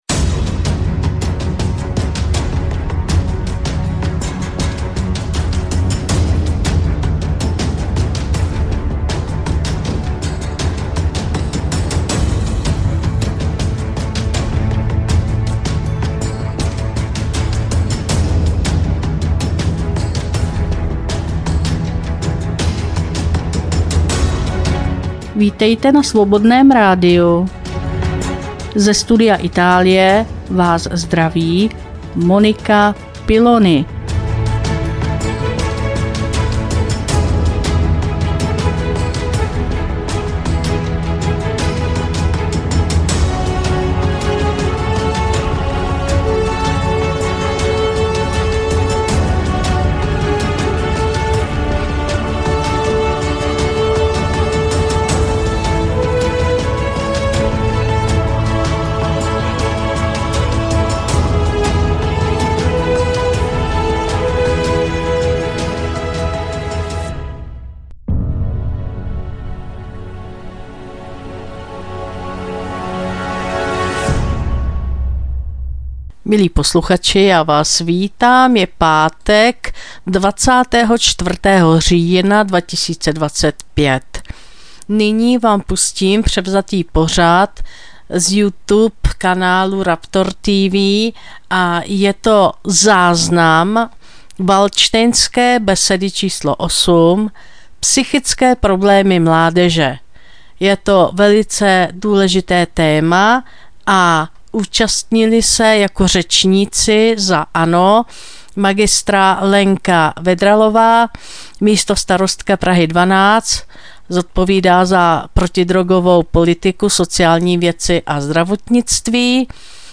2025-10-24 – Studio Itálie – záznam z Valdštejnské besedy na téma: Psychické problémy mládeže